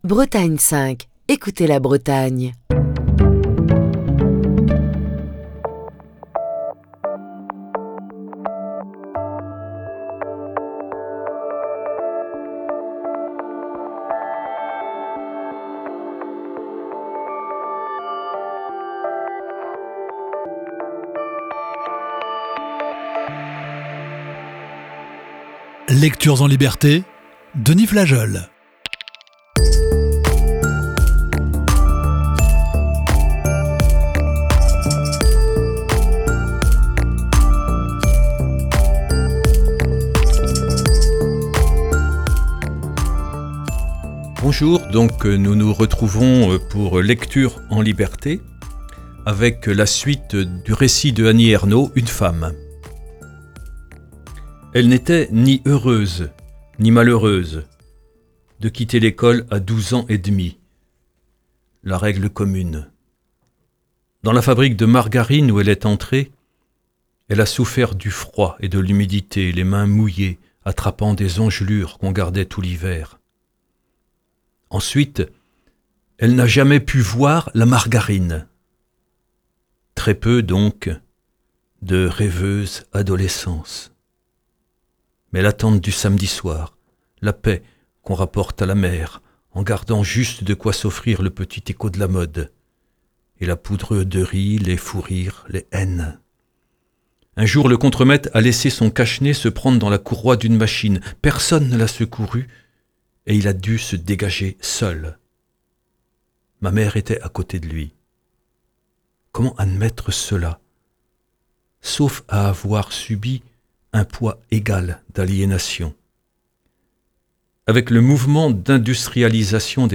Émission du 13 février 2024.